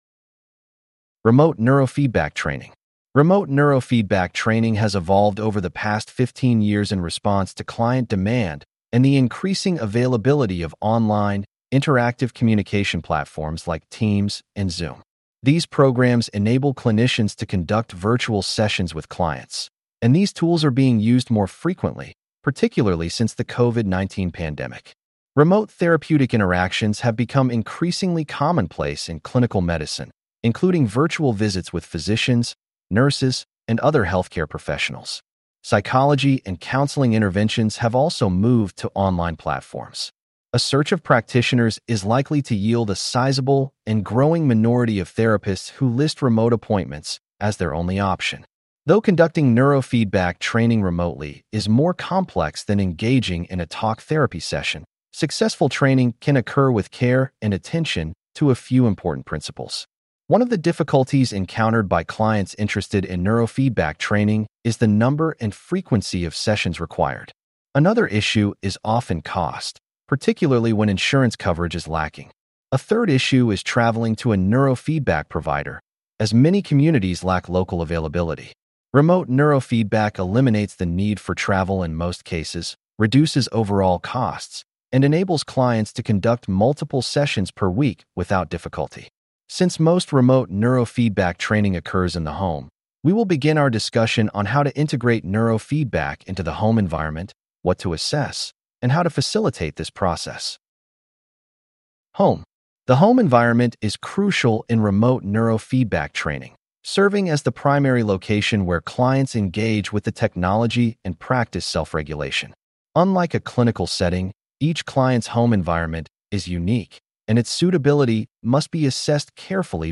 Please click on the podcast icon below to hear a full-length lecture.